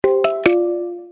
Kategorien SMS Töne